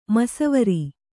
♪ masavari